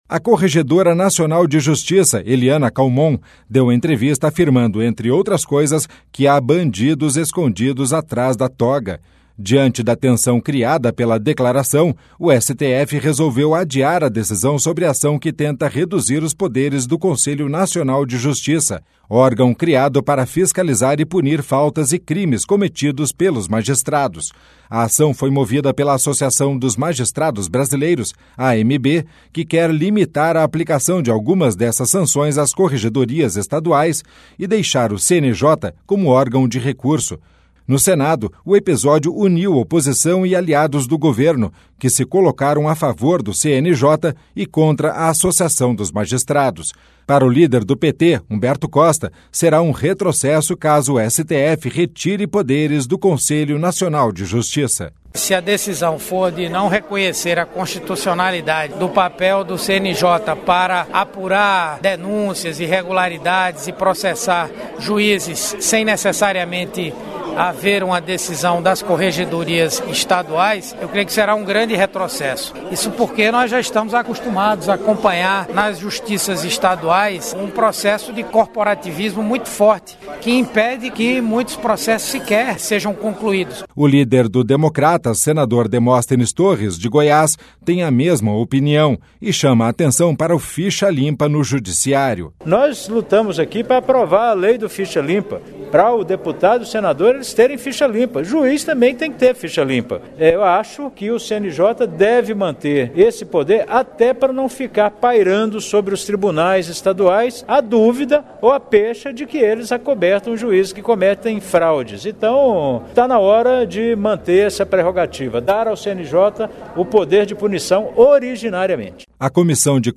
Para o líder do PT, Humberto Costa, será um retrocesso caso o STF retire poderes do Conselho Nacional de Justiça: